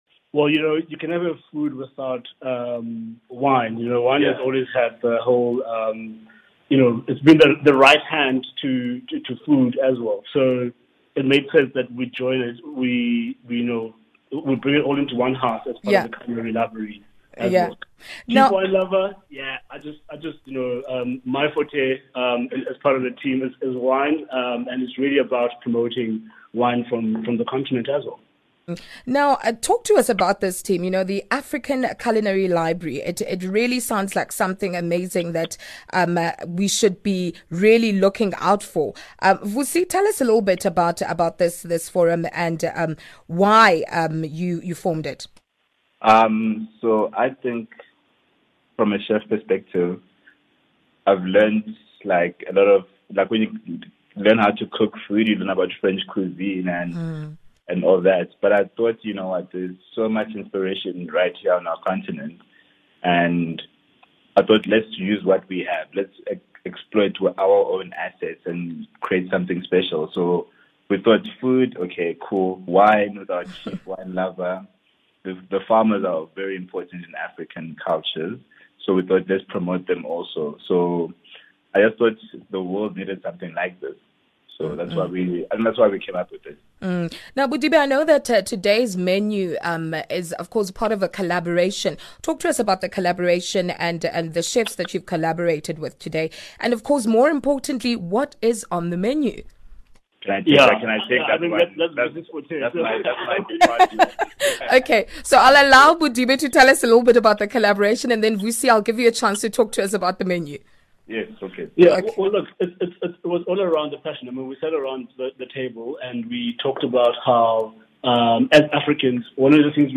African Channel – Radio Interview